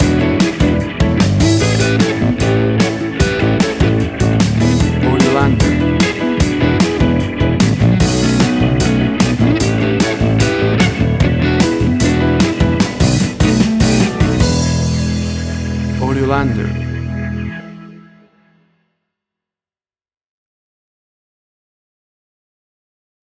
A cool swinging sixties Blues Rock music track.
Tempo (BPM): 150